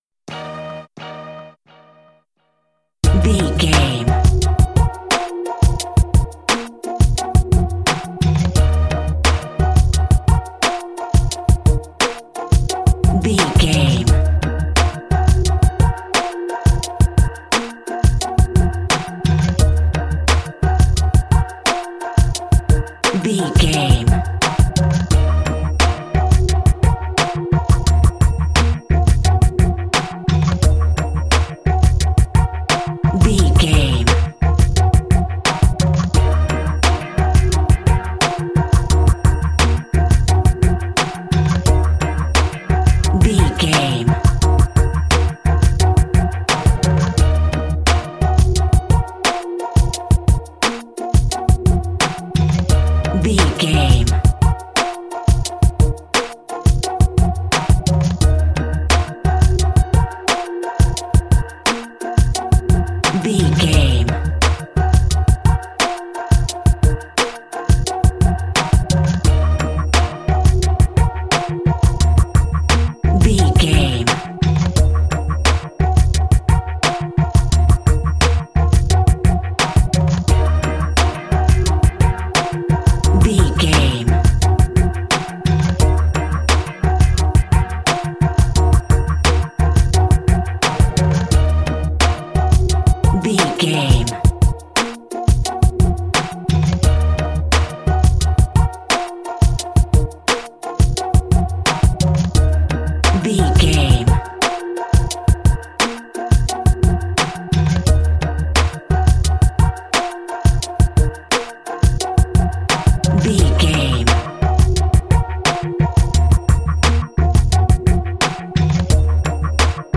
Aeolian/Minor
E♭
drum machine
synthesiser
percussion
hip hop
soul
Funk
acid jazz
energetic
bouncy
funky